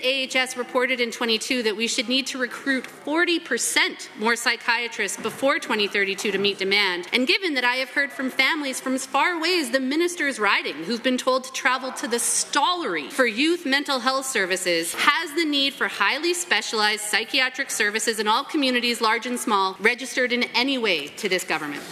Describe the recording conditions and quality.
Legislature Debates On Local Psychiatric Care